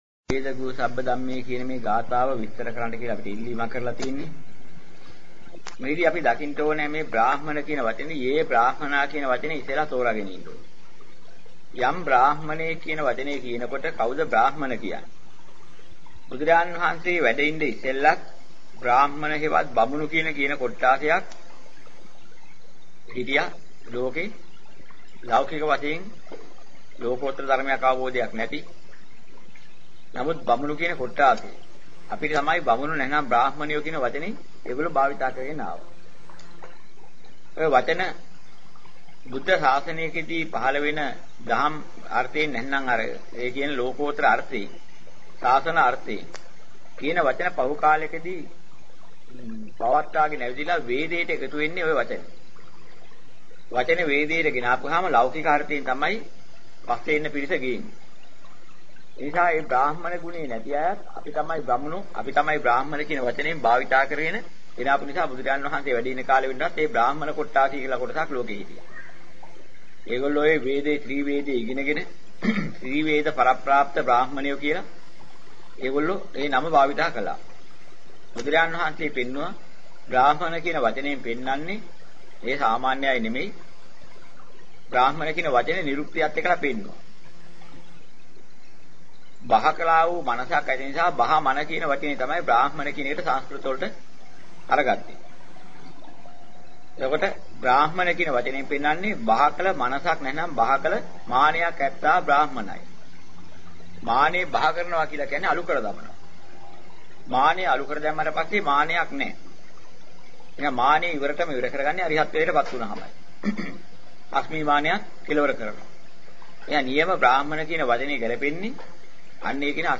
මෙම දේශනාවේ අඩංගු ගාථා හෝ සූත්‍ර කොටස්